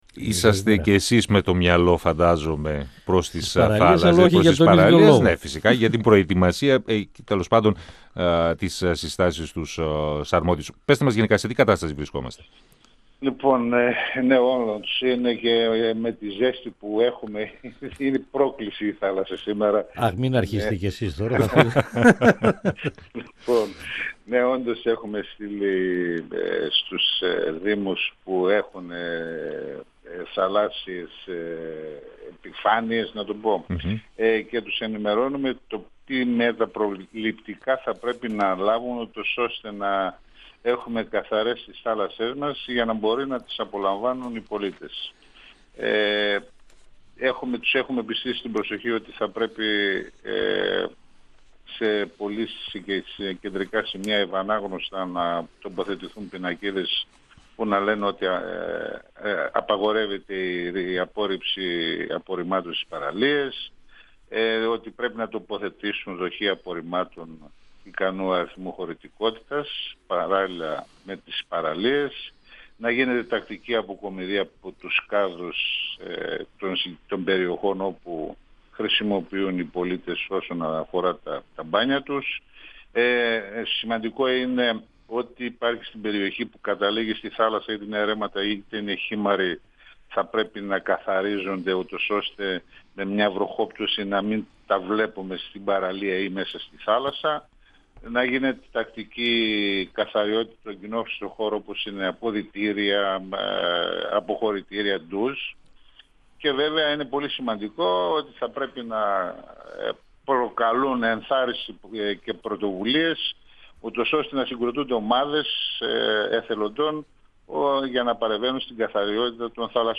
Ο αντιπεριφερειάρχης Δημόσιας Υγείας και Κοινωνικής Αλληλεγγύης, Δημήτρης Χατζηβρέττας, στον 102FM του Ρ.Σ.Μ. της ΕΡΤ3
Συνέντευξη